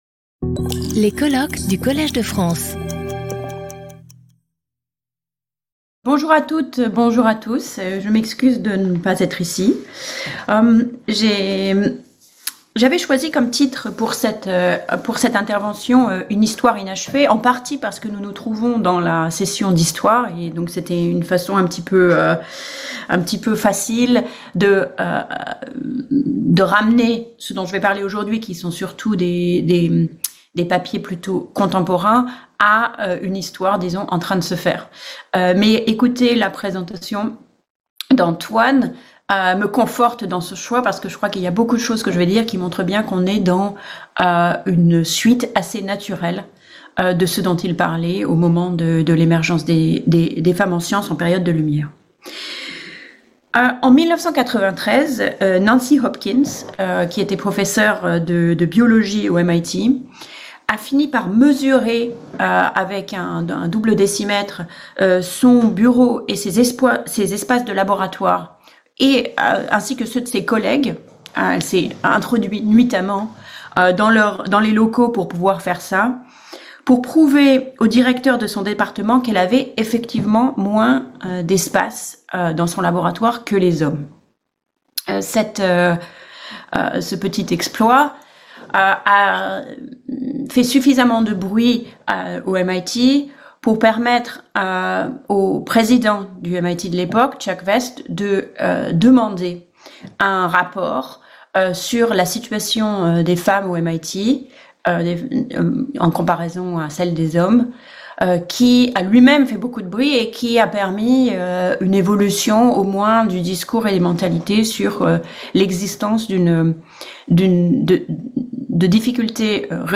Sauter le player vidéo Youtube Écouter l'audio Télécharger l'audio Lecture audio Séance animée par Patrick Boucheron. Chaque communication de 30 minutes est suivie de 10 minutes de discussion.